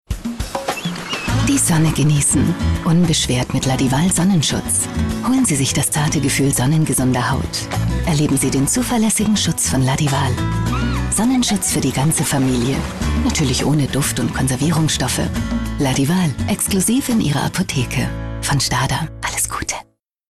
Werbung Hochdeutsch (DE)
Sprecherin.